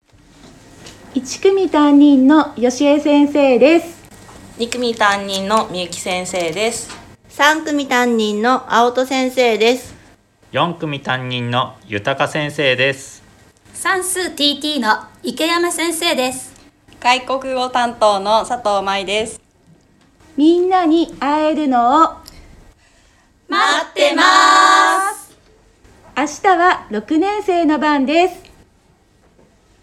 [ プレイヤーが見えない場合はこちらをクリック ] 先生方の自己紹介です。